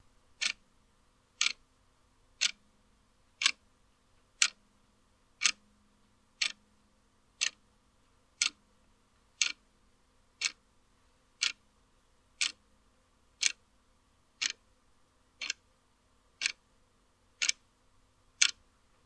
闹钟点击
描述：一个普通的塑料闹钟，咔咔作响
标签： 时钟 点击 塑料
声道立体声